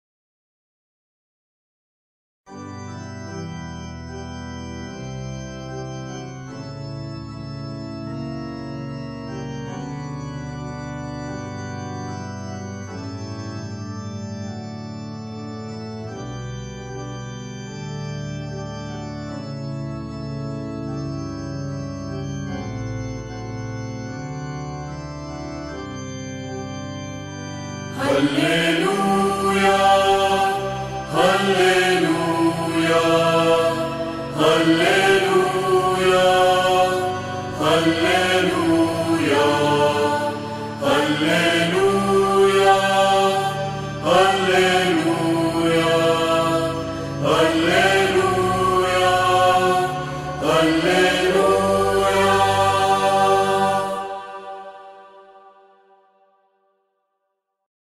92 هللويا (7)، (لحن بولندي)